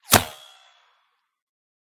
whine_7.ogg